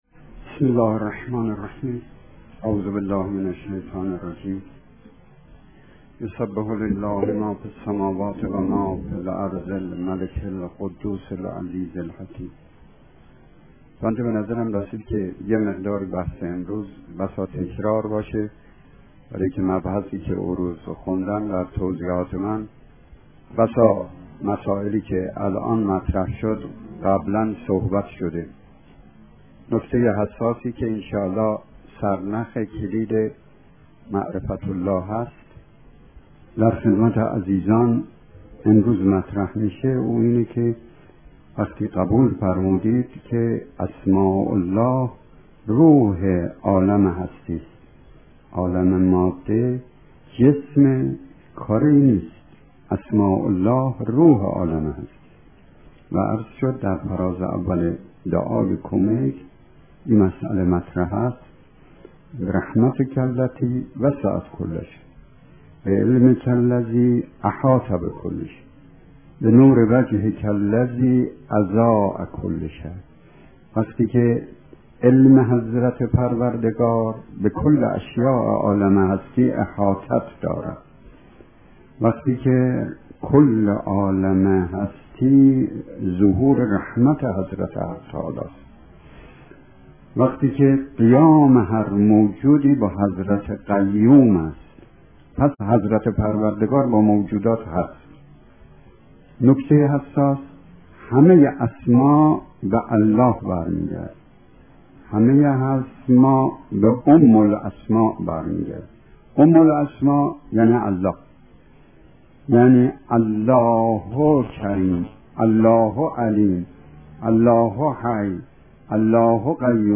جلسات سخنرانی استاد